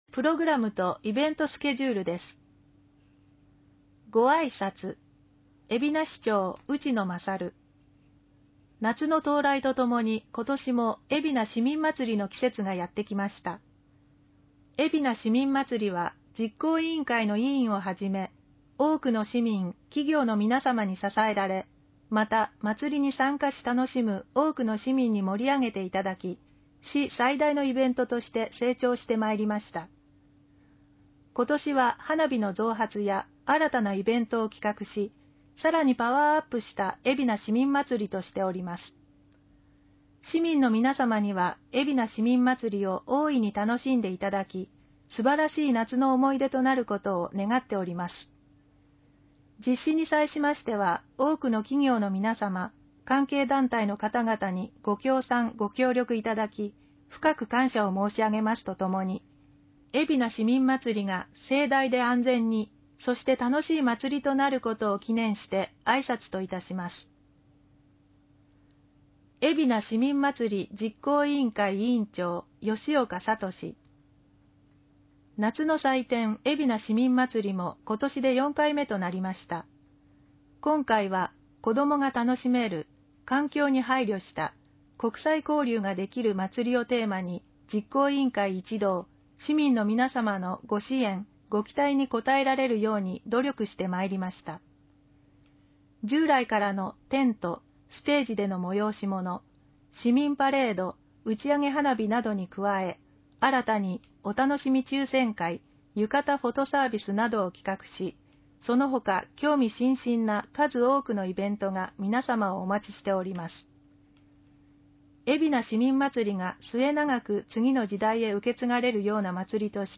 音声版は、音声訳ボランティア「矢ぐるまの会」のご協力により、同会が視覚障がい者の方のために作成したものを登載しています